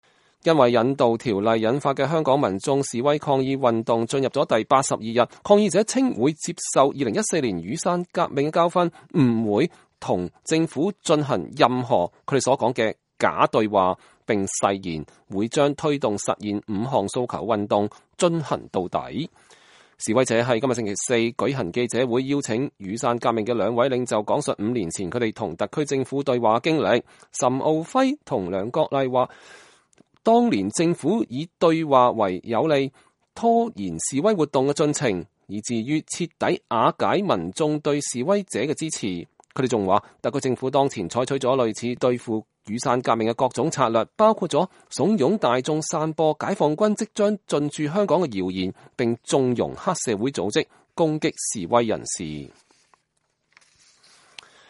示威者星期四舉行記者會，邀請雨傘革命的兩位領袖講述五年前他們與特區政府對話的經歷。
一位蒙面的示威人士在記者會上說，如果解放軍開進香港鎮壓示威行動，那意味著一國兩制的終結。